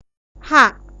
{ha.htô:} sound of medial former {ha.} ह